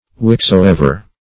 Search Result for " whichsoever" : The Collaborative International Dictionary of English v.0.48: Whichever \Which*ev"er\, Whichsoever \Which`so*ev"er\, pron.